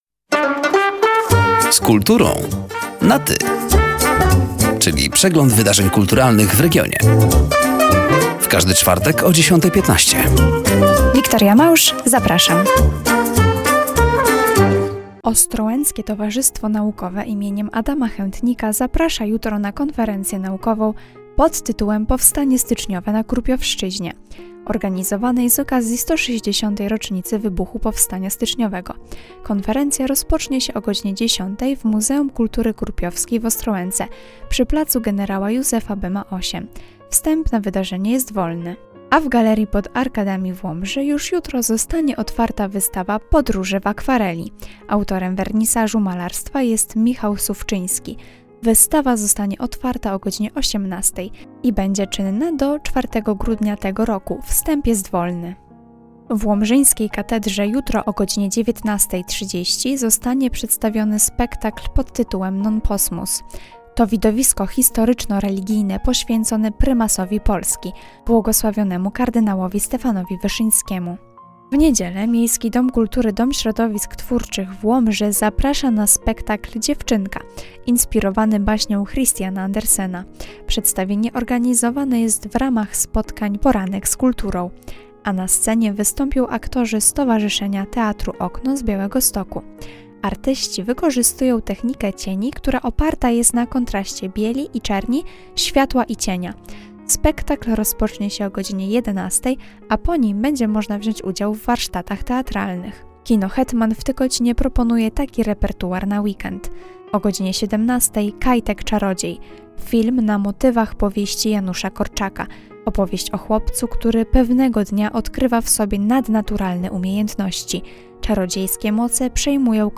Zapraszamy do zapoznania się z innymi zbliżającymi się wydarzeniami kulturalnymi oraz do wysłuchania rozmowy.